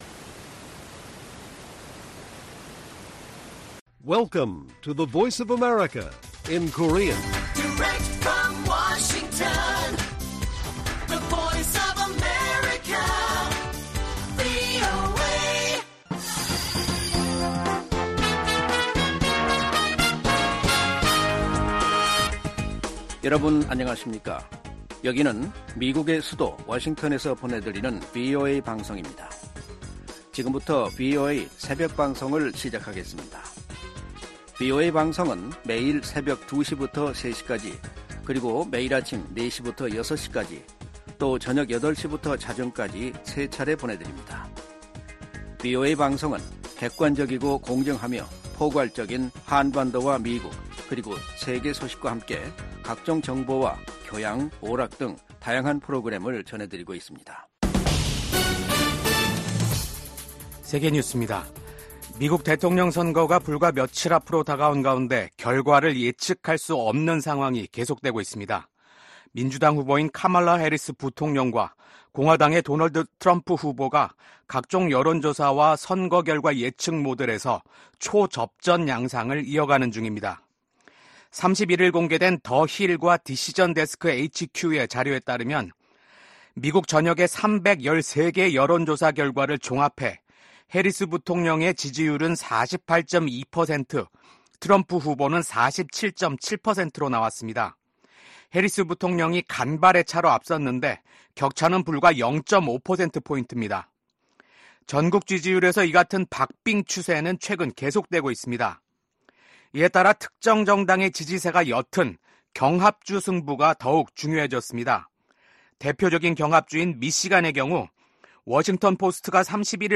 VOA 한국어 '출발 뉴스 쇼', 2024년 11월 1일 방송입니다. 북한이 미국 대선을 닷새 앞두고 동해상으로 대륙간탄도미사일(ICBM)을 발사했습니다. 미국 백악관이 북한의 대륙간탄도미사일(ICBM) 발사를 규탄하고 북한에 불안정한 행동을 중단할 것을 촉구했습니다. 미국과 한국의 국방장관이 북한군의 러시아 파병을 한 목소리로 강력하게 규탄했습니다.